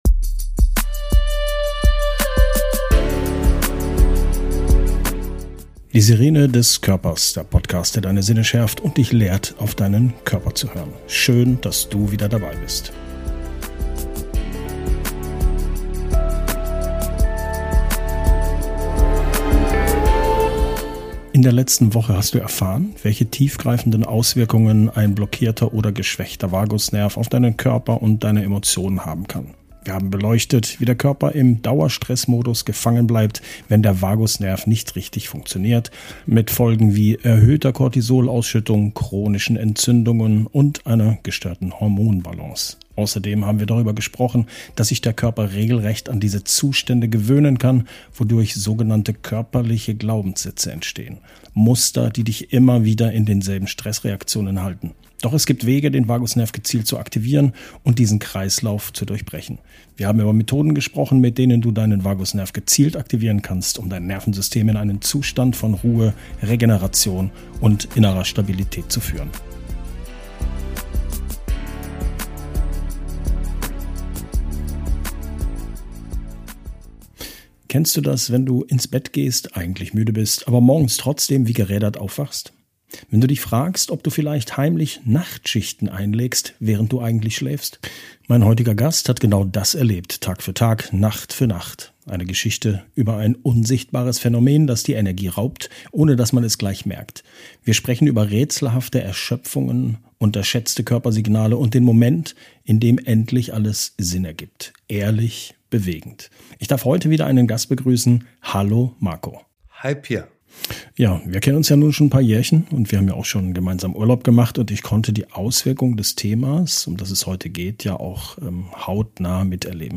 Beschreibung vor 1 Jahr In dieser Folge spreche ich mit meinem Gast über seine ganz persönliche Reise durch ein Thema, das oft lange unentdeckt bleibt – und dennoch den Alltag massiv beeinflusst: nächtliche Atemaussetzer. Er erzählt offen von den ersten Anzeichen, der oft unterschätzten Erschöpfung am Tag, den Herausforderungen auf dem Weg zur Diagnose und dem Leben mit Hilfsmitteln wie einer Atemmaske.